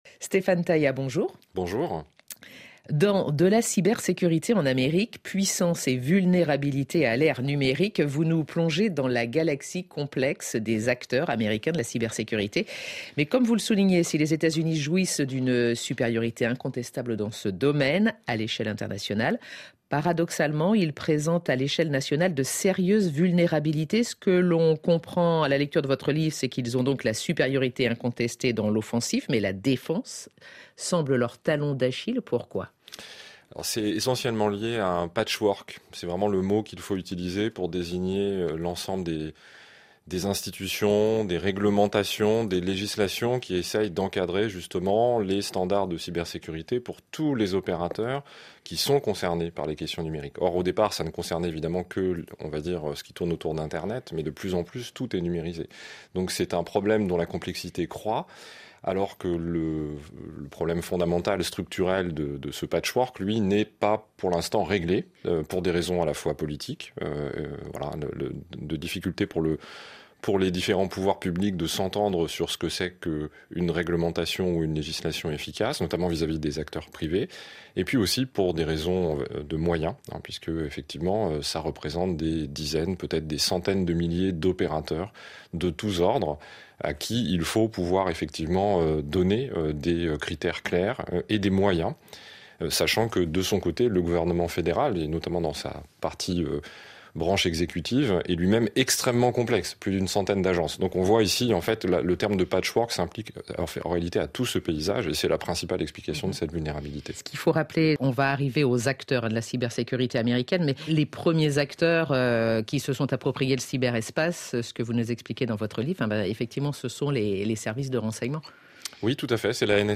Tous les samedis, un journaliste de la rédaction rencontre un auteur de livre consacré à l’actualité internationale.